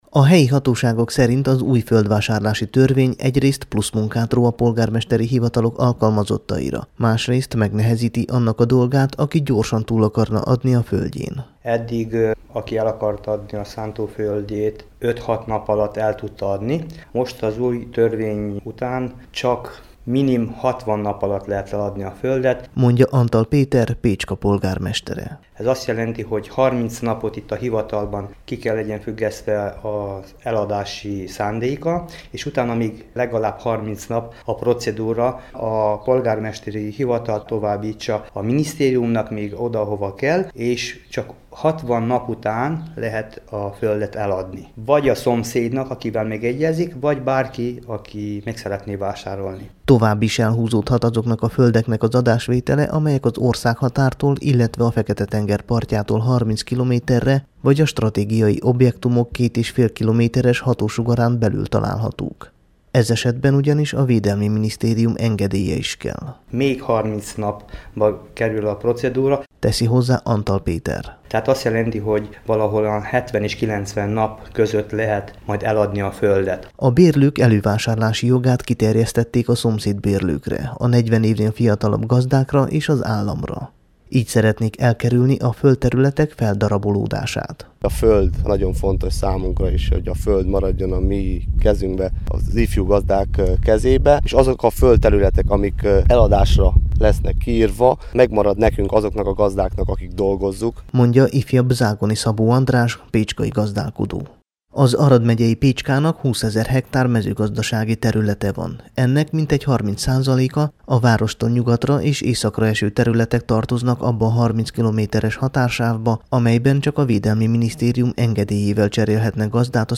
Pécskán készült a Temesvári Rádió számára.